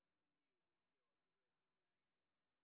sp02_street_snr0.wav